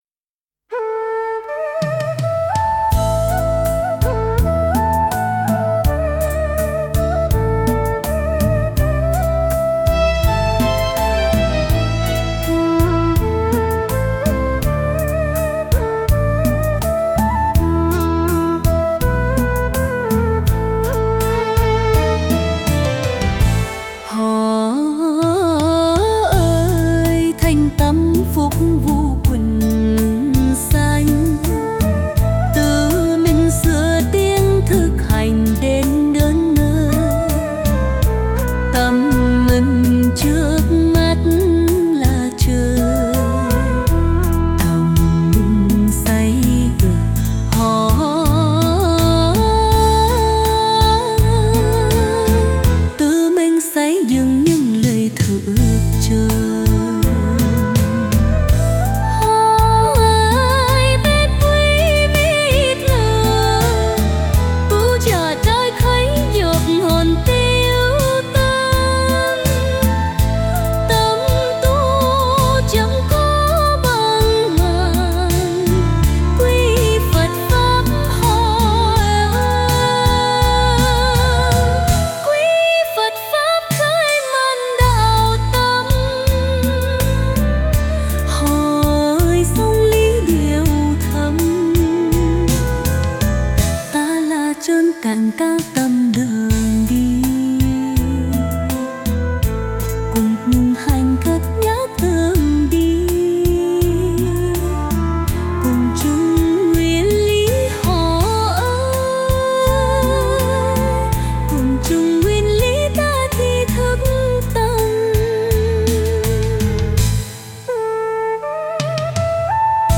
89-Quan-thong-03-nu-cao.mp3